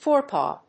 fóre・pàw